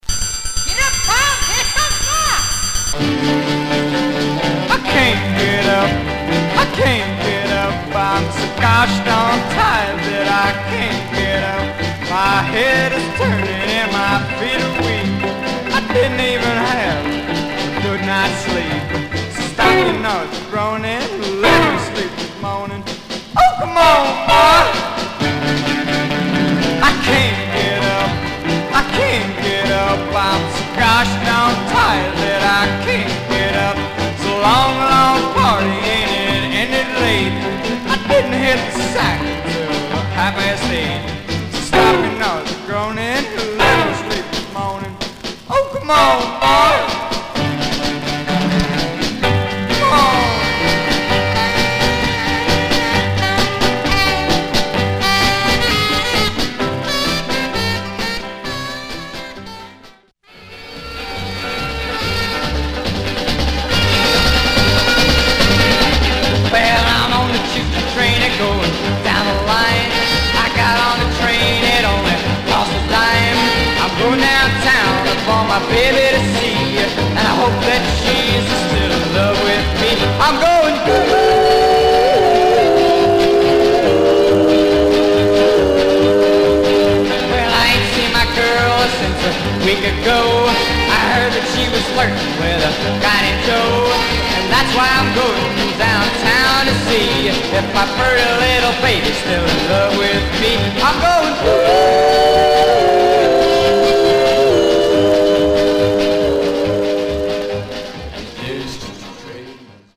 Surface noise/wear Stereo/mono Mono
Rockabilly